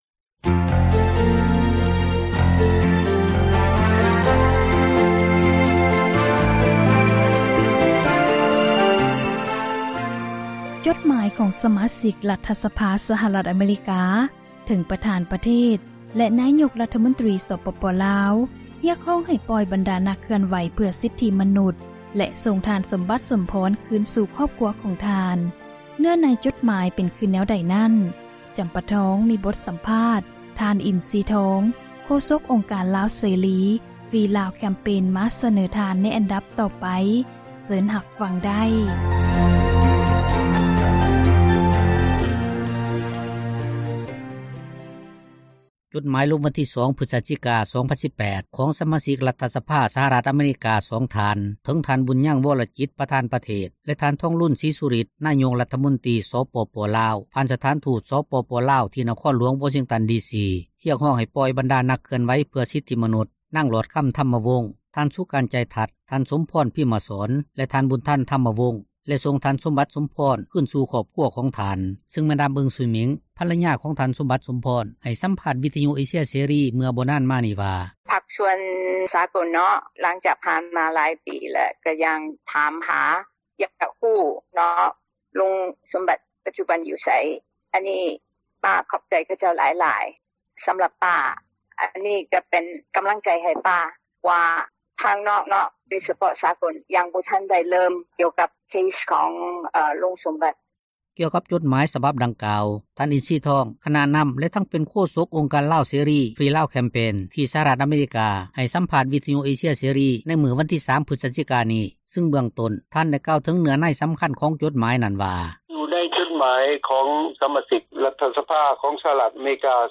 ເຊີນທ່ານຟັງ ສຽງສໍາພາດ ໄດ້ເລີຍ....